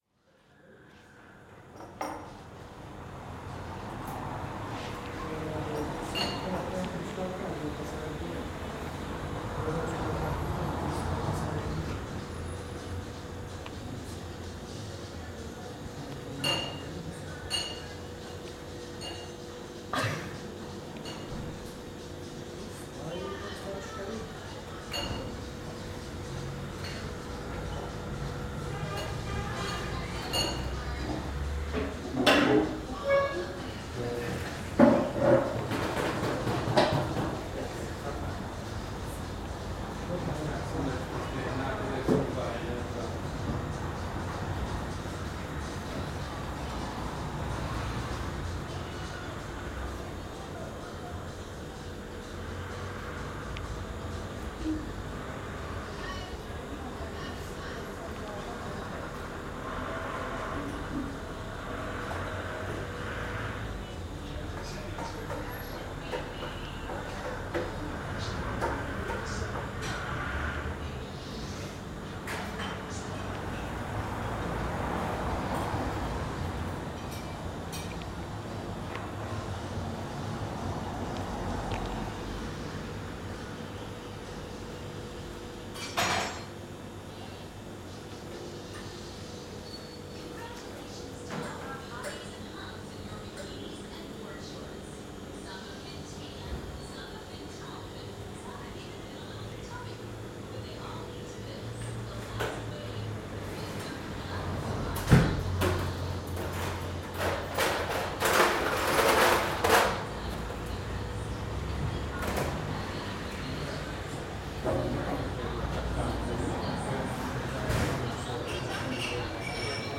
NODAR.00107 – Viseu: Av. Emidio Navarro – Dentro de café
Dentro de um dos inúmeros cafés que preenchem a cidade de Viseu. Gravado com Edirol R44 e um par de microfones de lapela Audio-Technica AT899.
Paisagem Sonora Rural
Viseu-Av.-Emidio-Navarro-Dentro-de-café.mp3